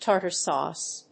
アクセントtártar sàuce